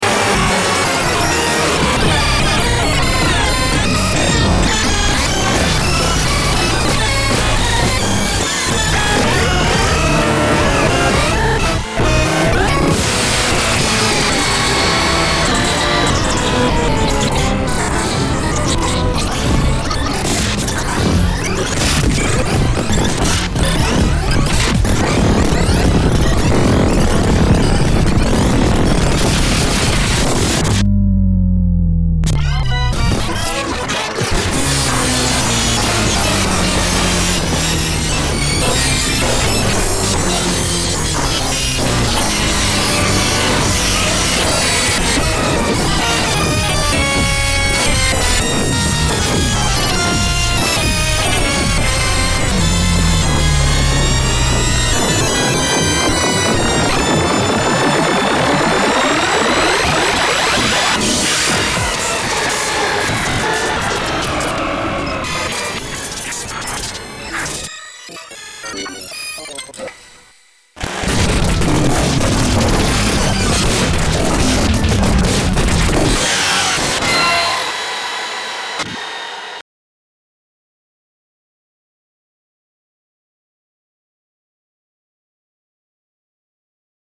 Pitch-bombed remix of a mashup of a mashup
original songs unrecognizable.